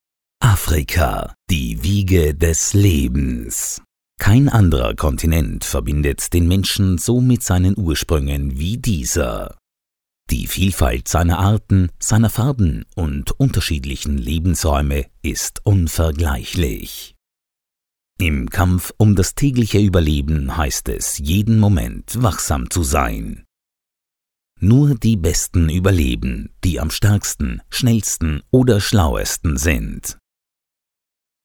My natural voice tone is warm and friendly, with a casual, corporate, energetic or uplifting style, as required, suiting all types of projects.